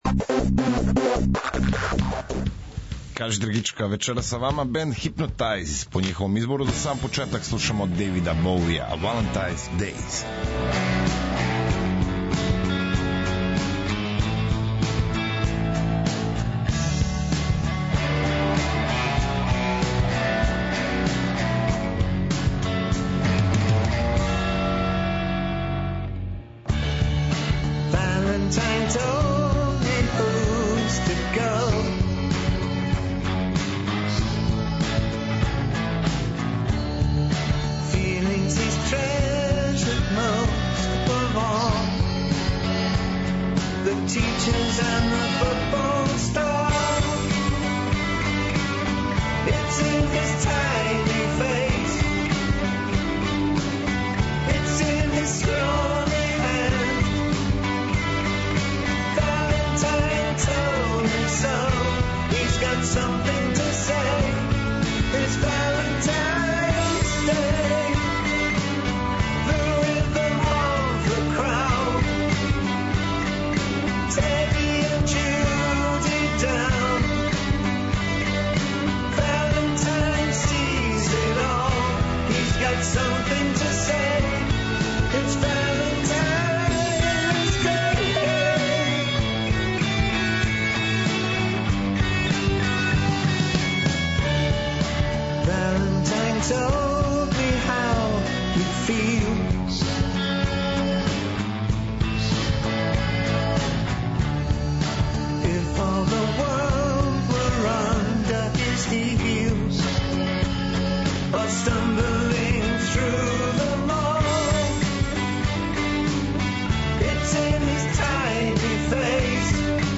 Вечерас се дружимо са групом ''Hypnotized''! Момци нам доносе нови сингл којим најављују трећи албум.